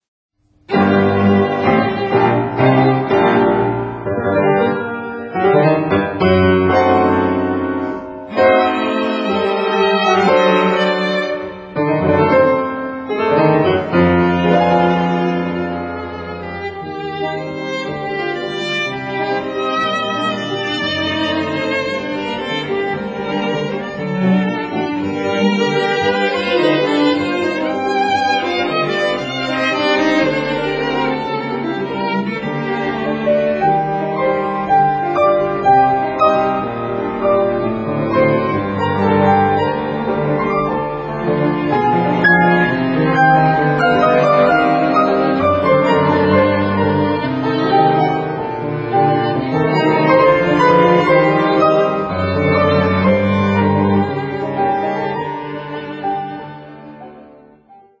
Frédéric CHOPIN: Trio für Klavier, Violine und Cello, g-moll, op.8